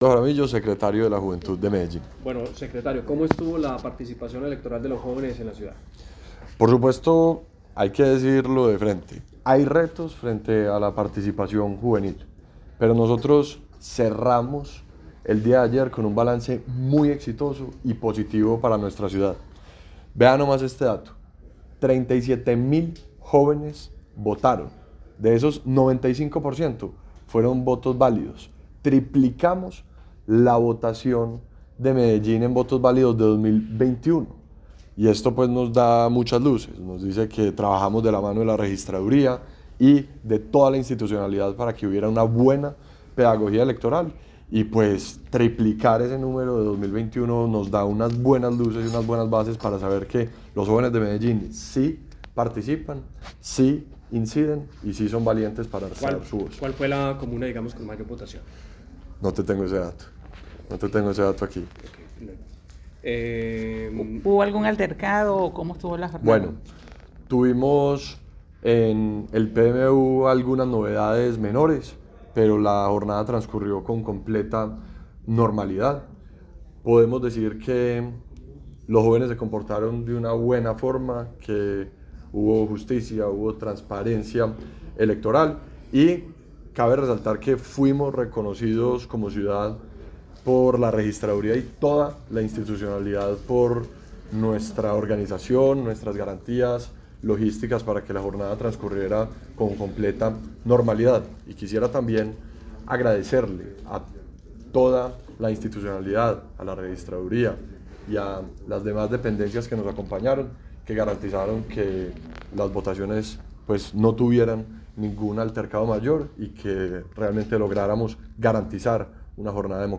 Declaraciones secretario de la Juventud, Ricardo Jaramillo Vélez
Declaraciones-secretario-de-la-Juventud-Ricardo-Jaramillo-Velez.mp3